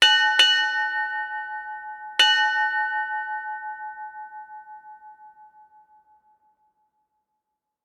Three Bells,Ship Time
3-bells bell ding maritime nautical naval sailing seafaring sound effect free sound royalty free Sound Effects